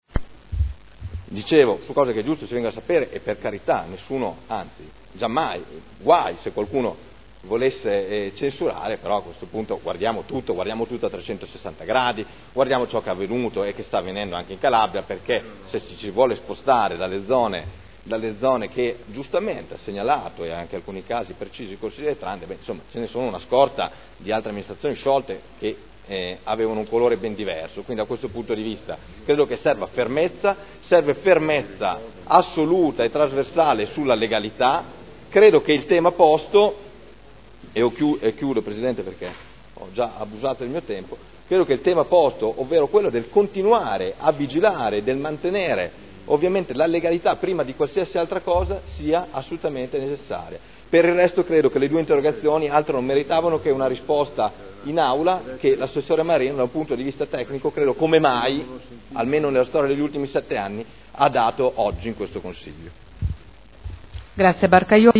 Michele Barcaiuolo — Sito Audio Consiglio Comunale
Seduta del 14/03/2011.